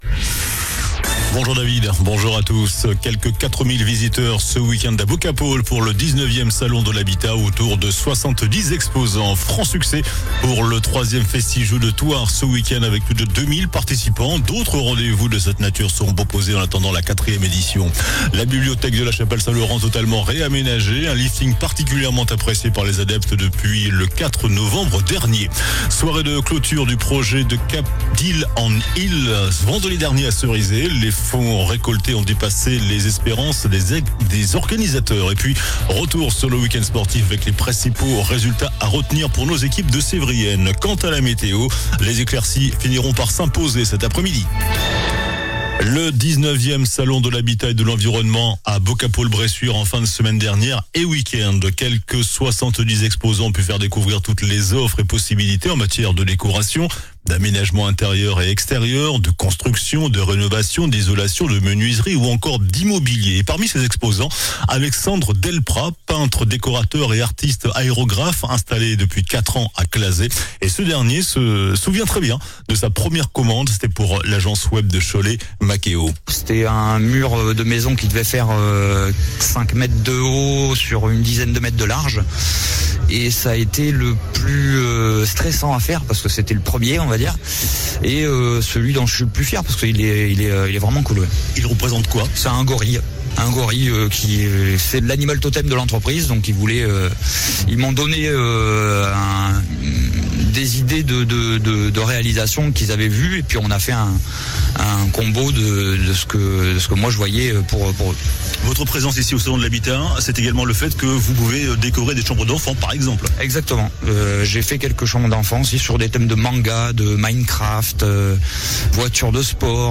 JOURNAL DU LUNDI 17 NOVEMBRE ( MIDI )